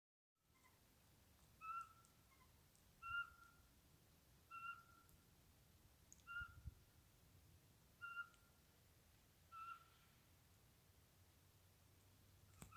Птицы -> Совообразные ->
воробьиный сыч, Glaucidium passerinum
СтатусПоёт